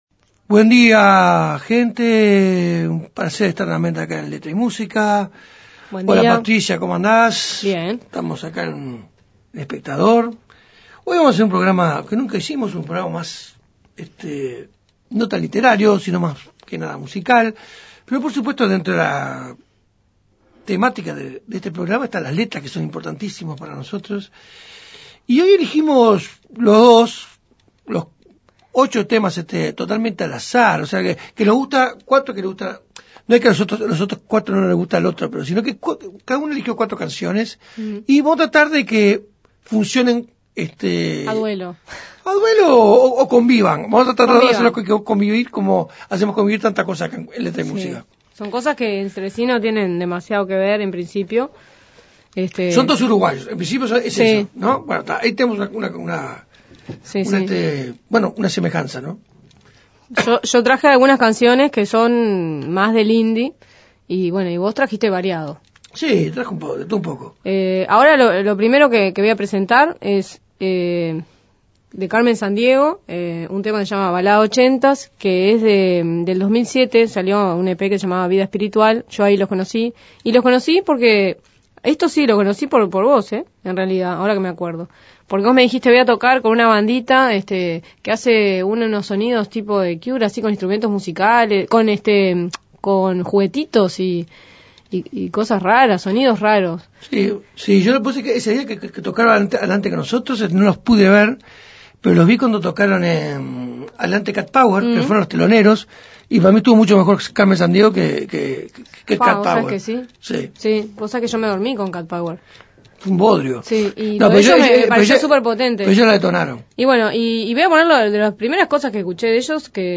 Este domingo los conductores de este programa se propusieron convivir en sus diferencias.